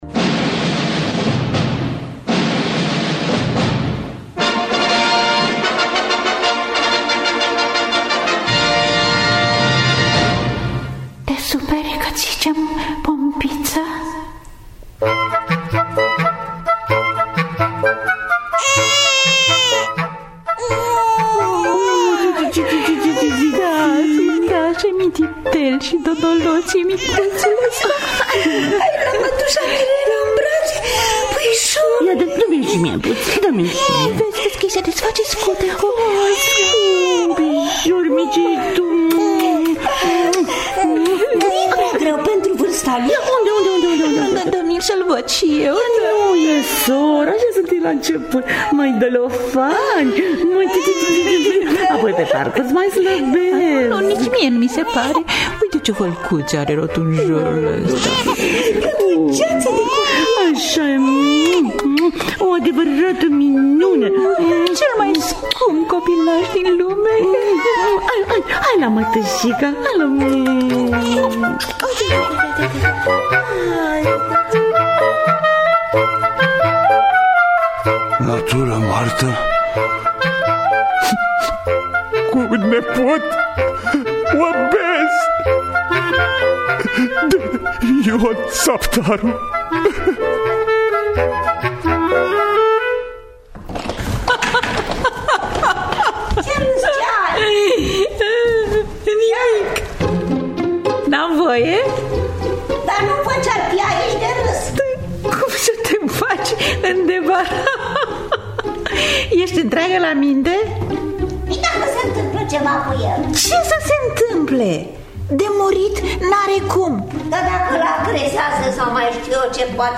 Natură moartă cu nepot obez de Ion Sapdaru – Teatru Radiofonic Online
Adaptarea radiofonicã